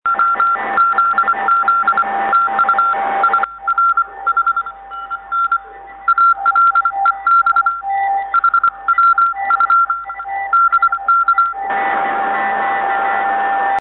la riduzione del rumore è drastica ed il segnale diventa ben comprensibile.
Tali files sono stati registrati in situazioni reali, sulle bande radioamatoriali.